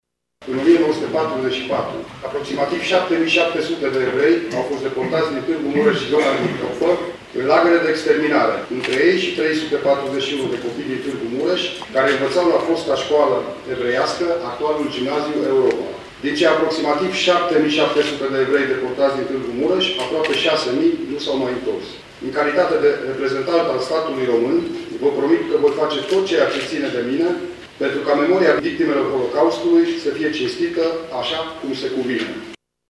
Ceremonia a avut loc astăzi, la Sala de Oglinzi a Palatului Culturii din Tîrgu-Mureș.
Distincțiile au fost oferite de prefectul de Mureș, Lucian Goga, care a amintit că mii de târgumureșeni au fost trimiși în lagărul de la Auschwitz – Birchenau pentru singura vină că erau evrei: